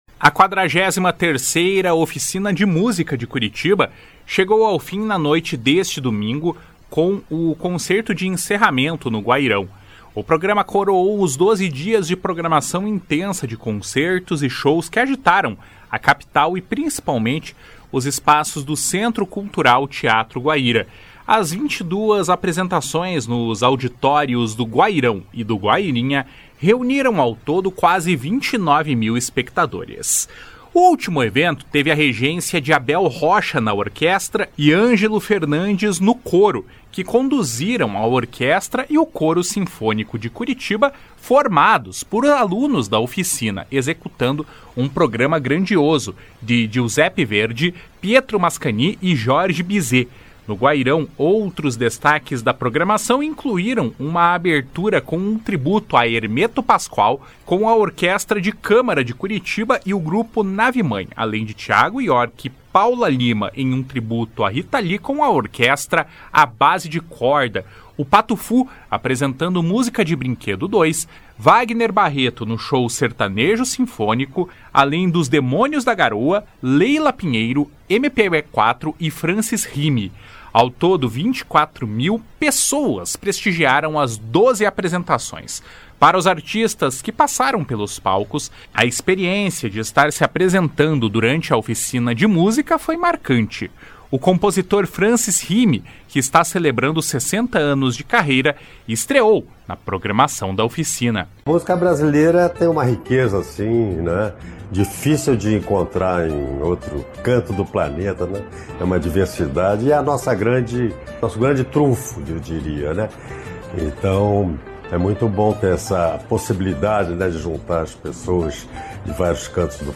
// SONORA FRANCIS HIME //